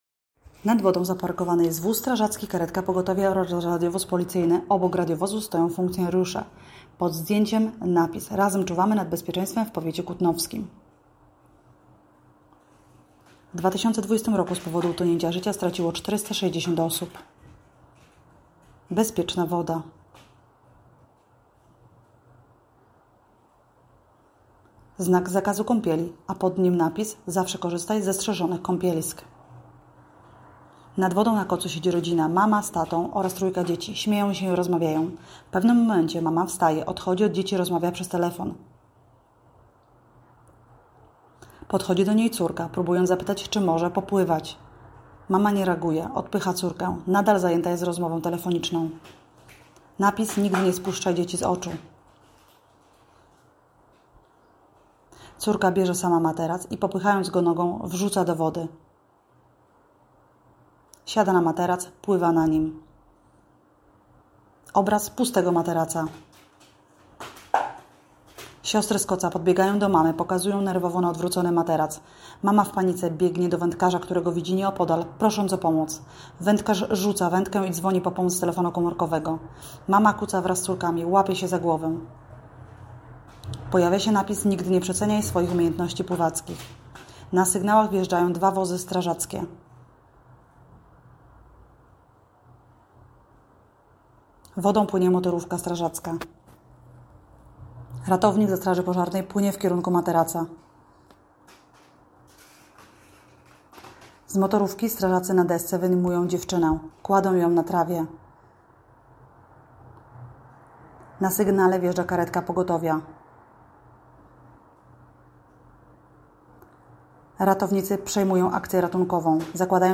Nagranie audio Audiodeskrypcja spotu